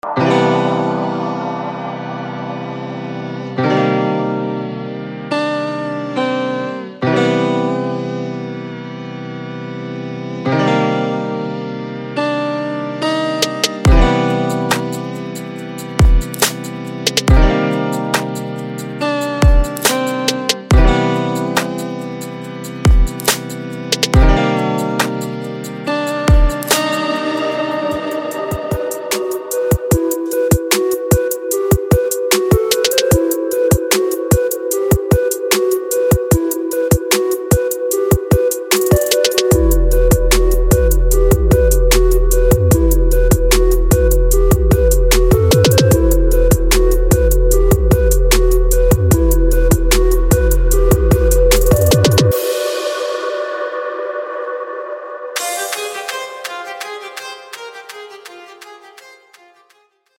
Leftfield Bass